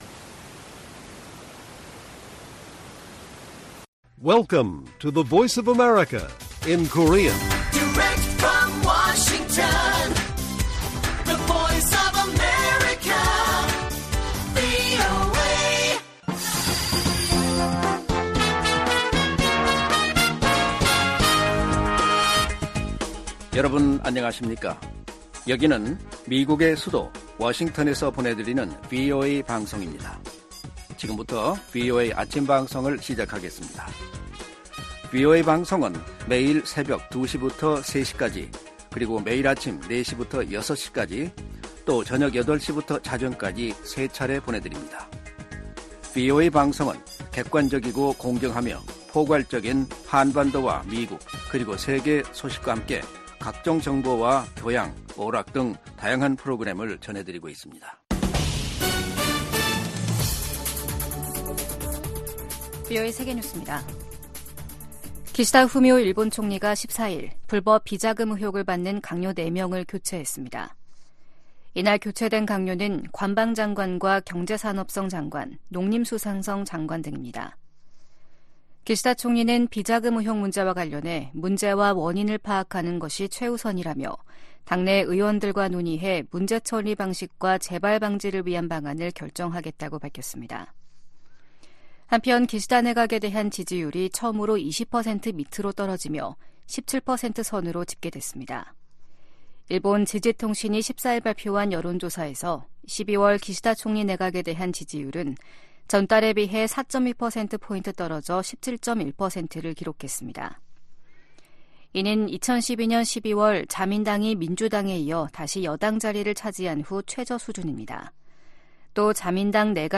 세계 뉴스와 함께 미국의 모든 것을 소개하는 '생방송 여기는 워싱턴입니다', 2023년 12월 15일 아침 방송입니다. '지구촌 오늘'에서는 유럽연합(EU) 정상들이 우크라이나의 가입 협상을 시작 여부를 논의하기 시작한 소식 전해드리고, '아메리카 나우'에서는 하원이 조 바이든 대통령 탄핵 조사 결의안을 통과시킨 이야기 살펴보겠습니다.